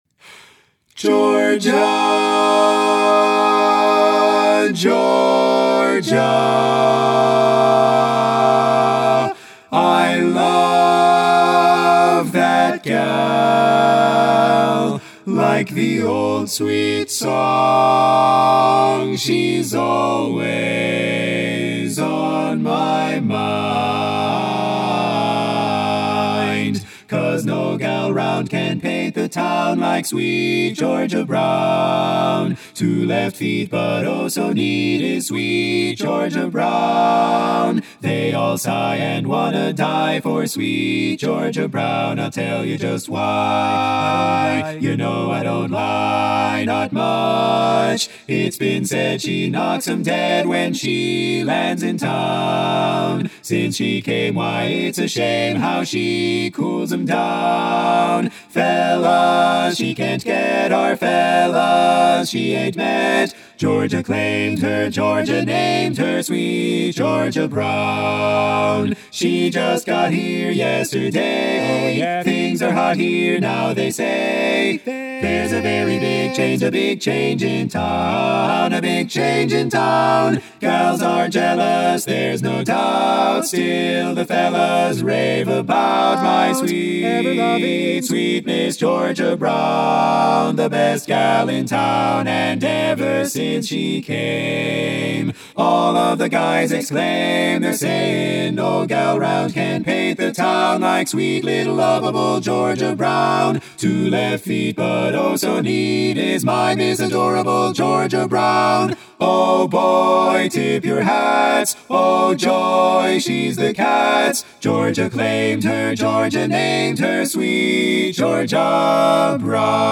Full Mix
Up-tempo
Barbershop
A♭ Major